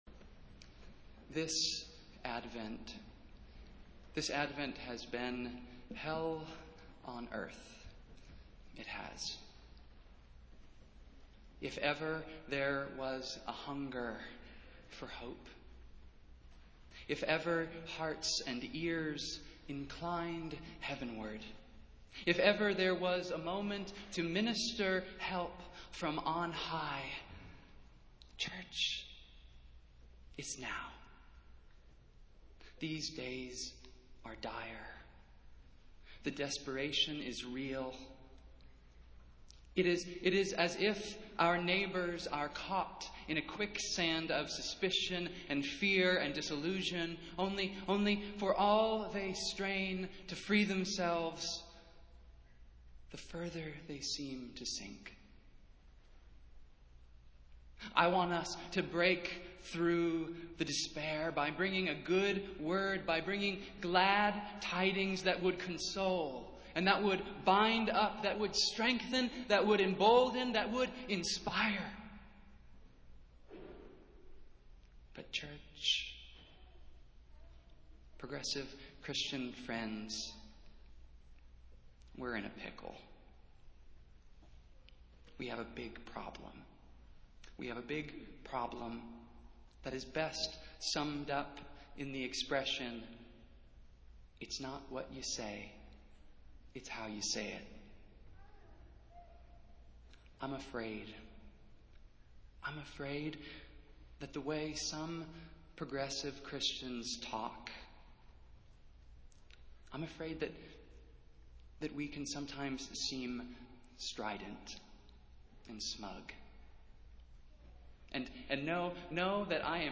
Festival Worship - Third Sunday in Advent